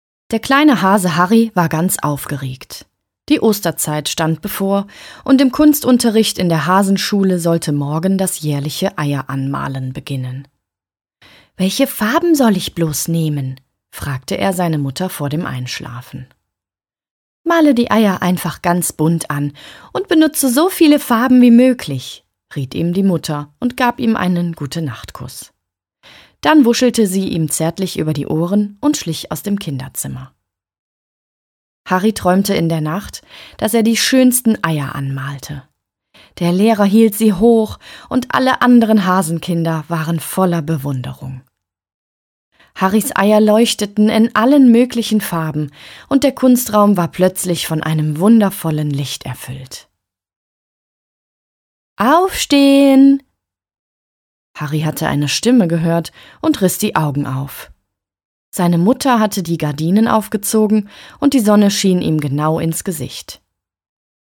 Ernest and Young Imagefilm